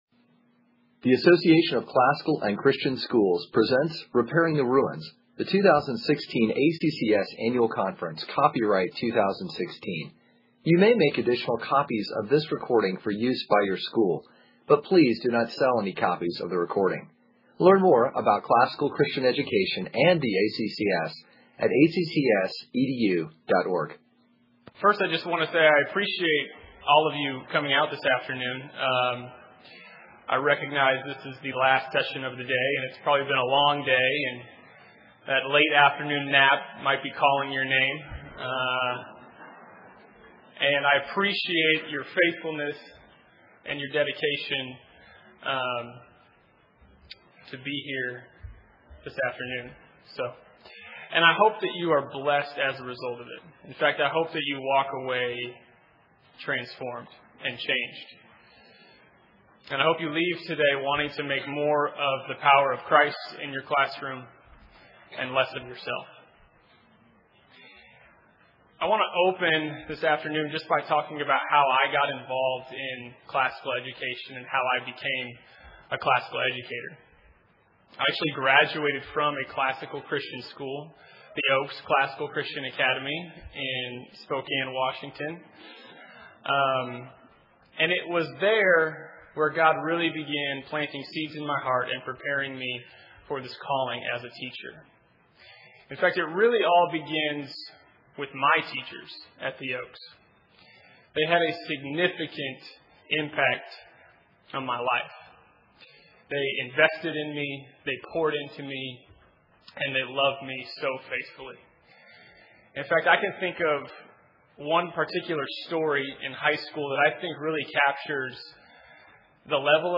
2016 Workshop Talk | 0:35:41 | All Grade Levels, Virtue, Character, Discipline